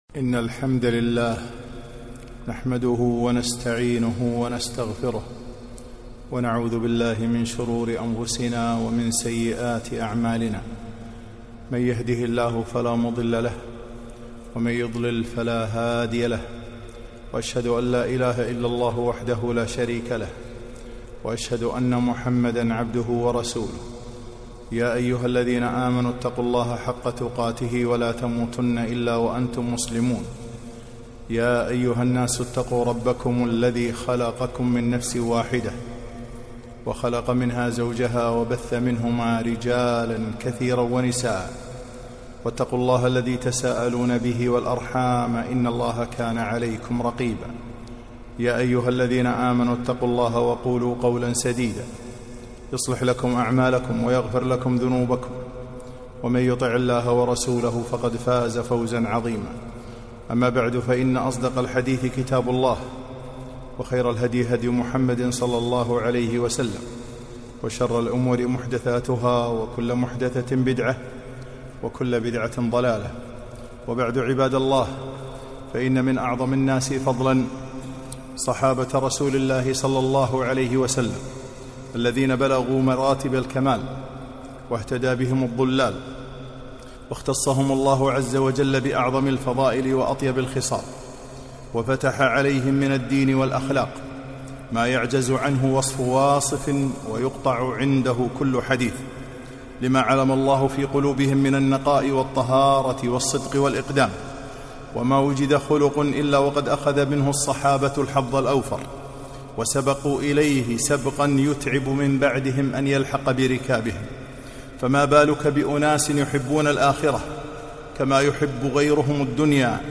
خطبة - حقووق الصحابة رضي الله عنهم